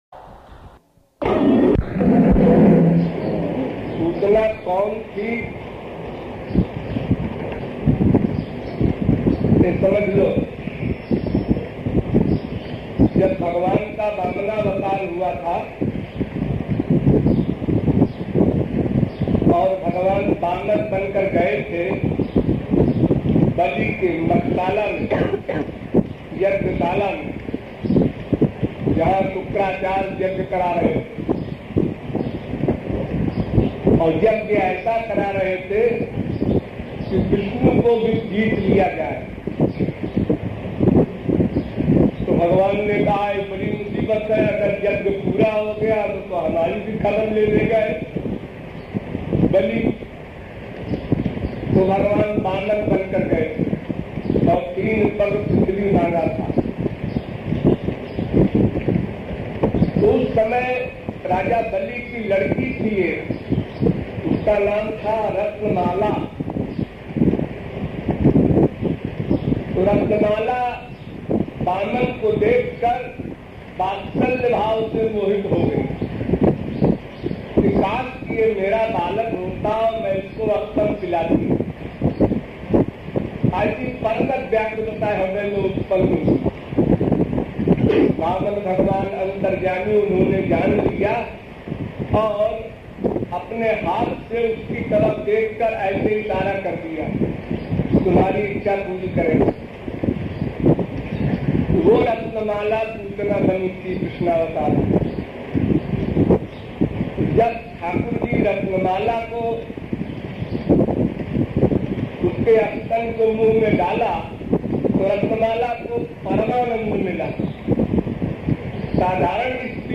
In this special podcast, you can listen to the enlightening lecture